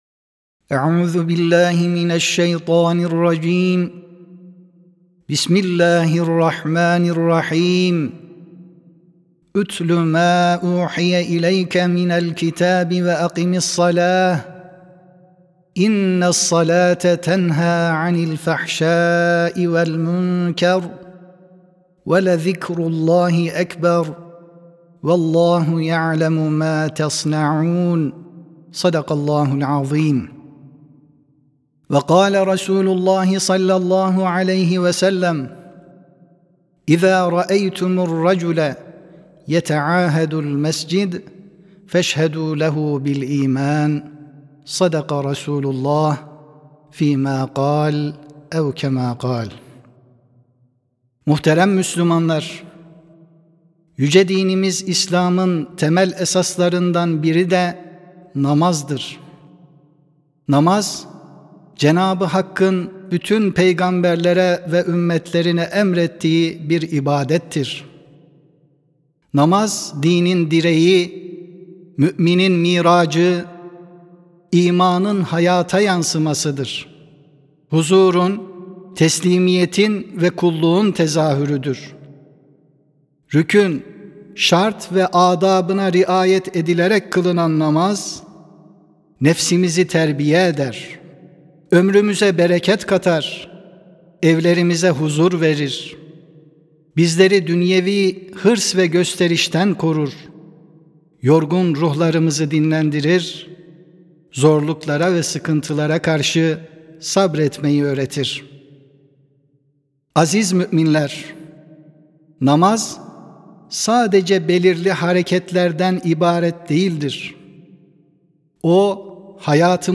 26 Eylül 2025 Tarihli Cuma Hutbesi
Sesli Hutbe (Peygamberimiz (s.a.s), Cami ve Namaz).mp3